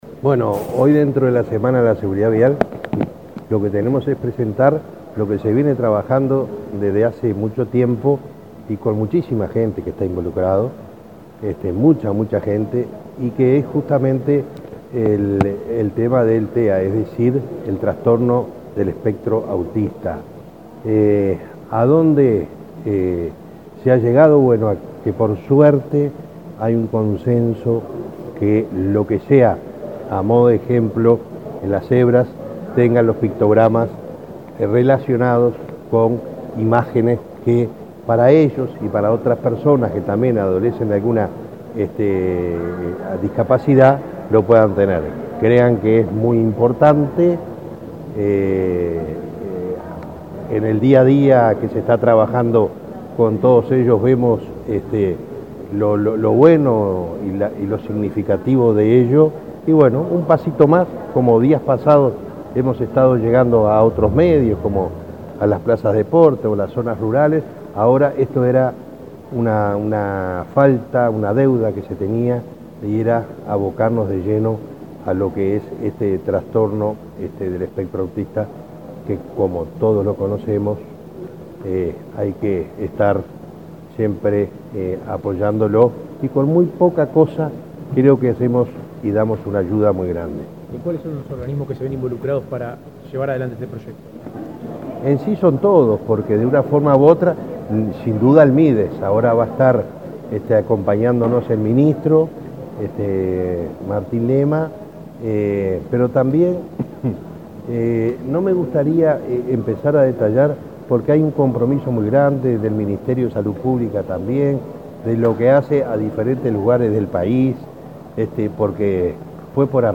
Declaraciones del presidente de Unasev, Alejandro Draper
El presidente de la Unidad Nacional de Seguridad Vial (Unasev), Alejandro Draper, dialogó con la prensa antes de participar, este viernes 13 en la